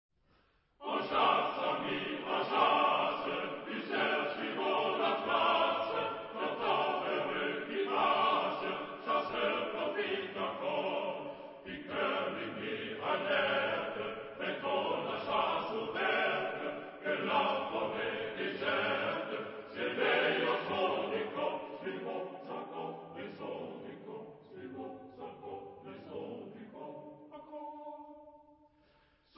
Genre-Style-Forme : Romantique ; Profane ; Lied
Type de choeur : TTBB  (4 voix égales d'hommes )
Tonalité : ré majeur